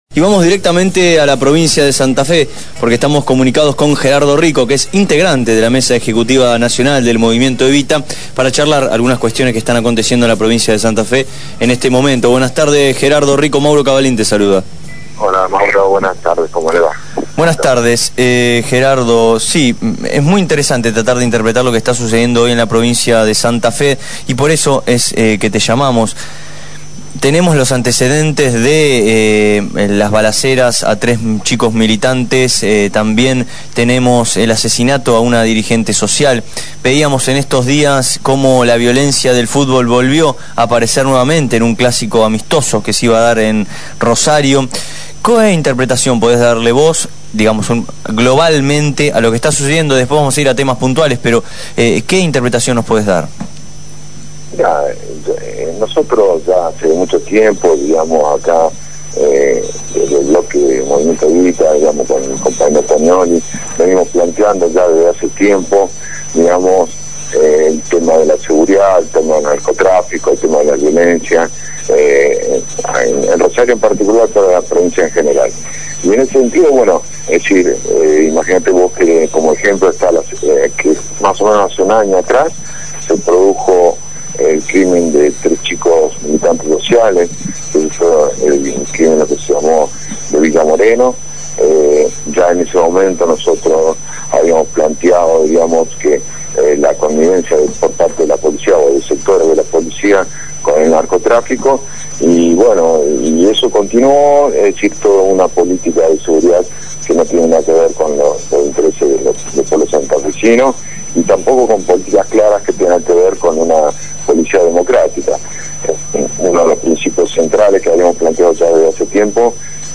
Gerardo Rico, diputado provincial de Santa Fe del Bloque Movimiento Evita habló con el programa “Abramos la Boca” de Radio Gráfica.